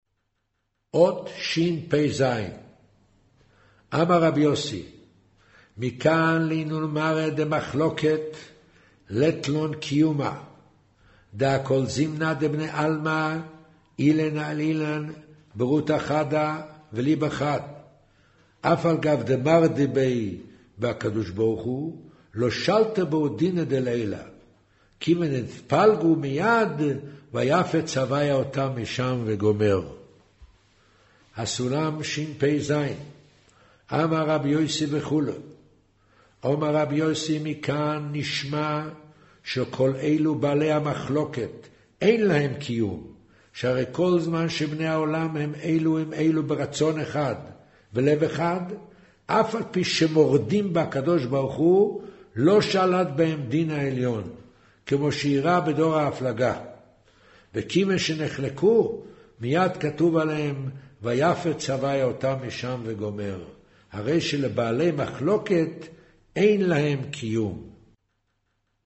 אודיו - קריינות זהר, פרשת נח, מאמר ויאמר ה' הן עם אחד